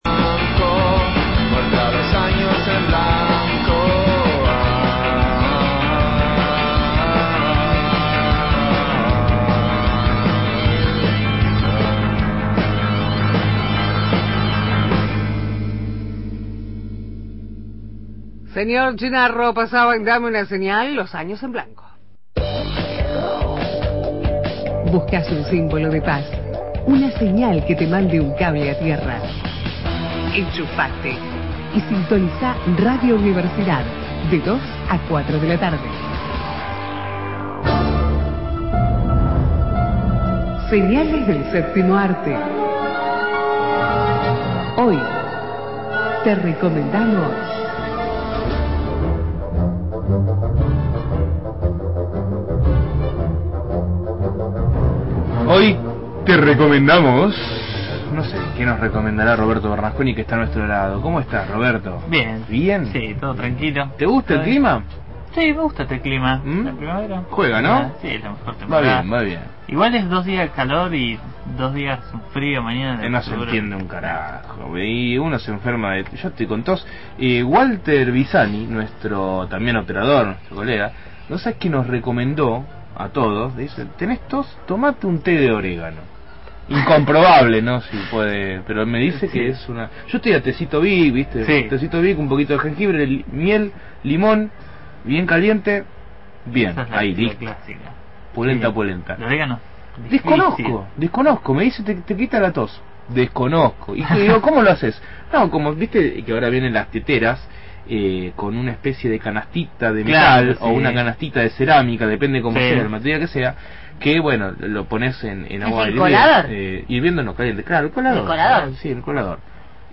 columna de cine